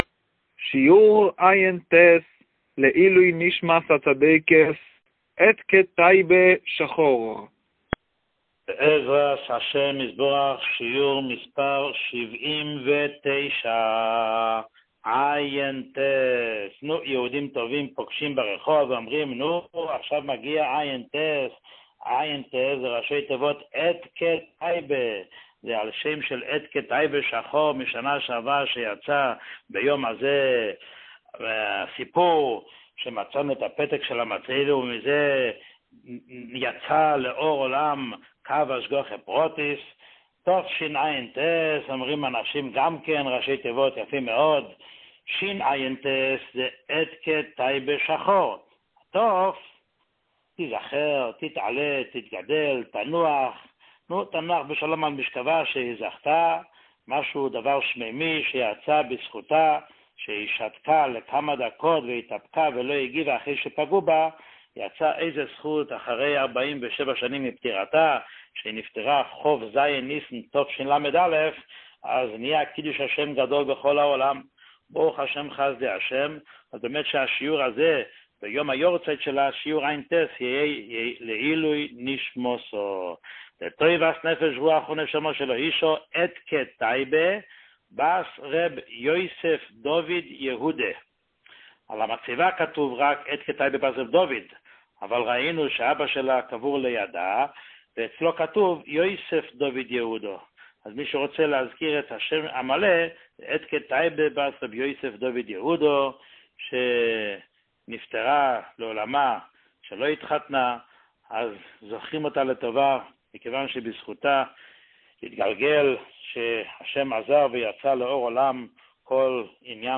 שיעור 79